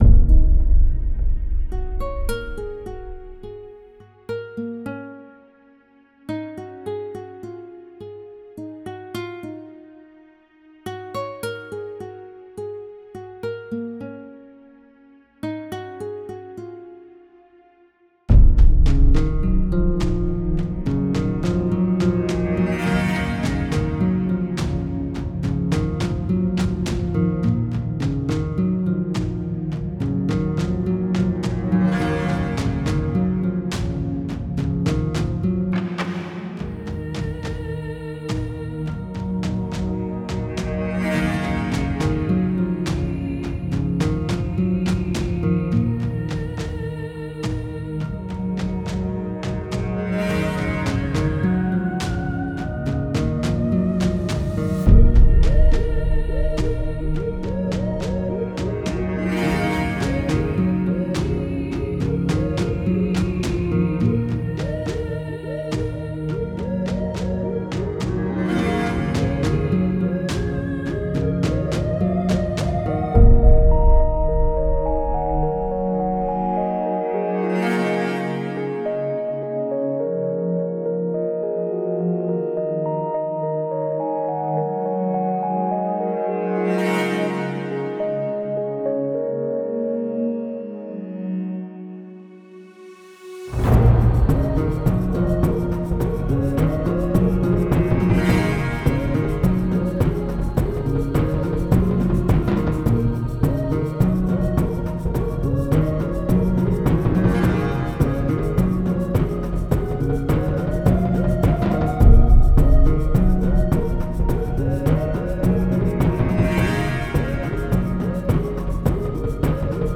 something medieval.